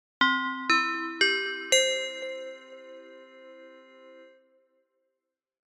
알림음 8_Correct1-ascending.mp3